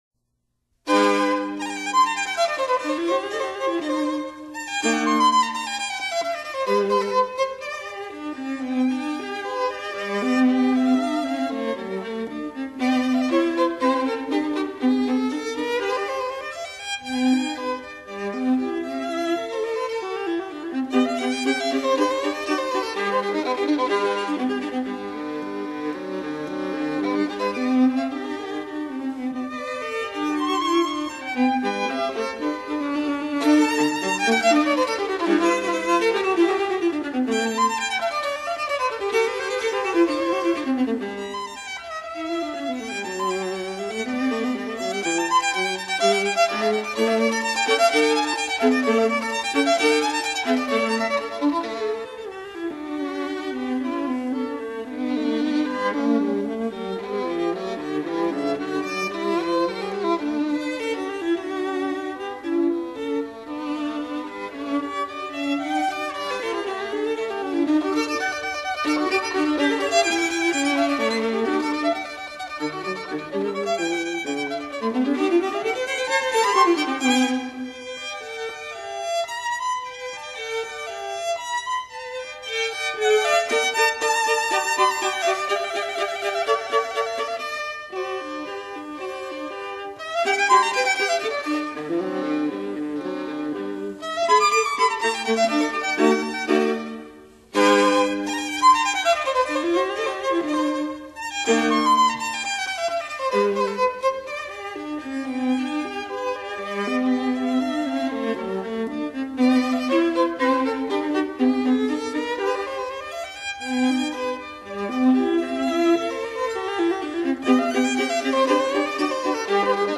violin
viola
弦乐音色美艳动人